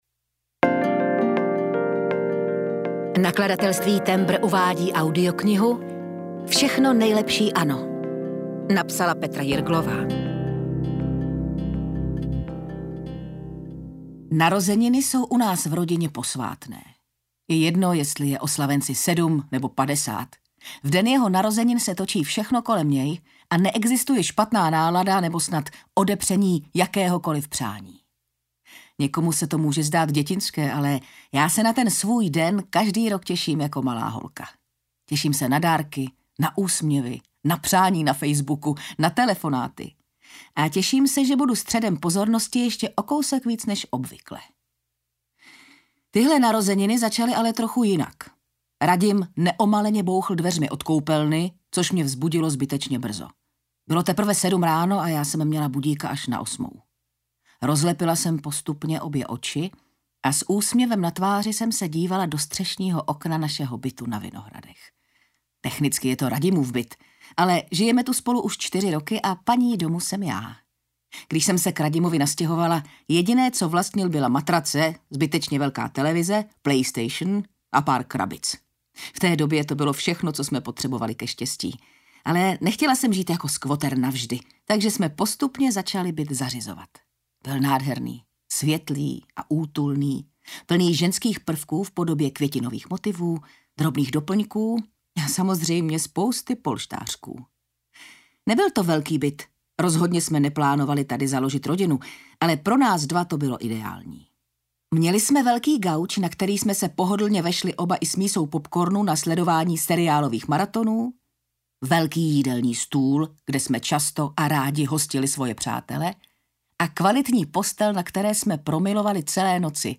Všechno nejlepší, Anno audiokniha
Ukázka z knihy
vsechno-nejlepsi-anno-audiokniha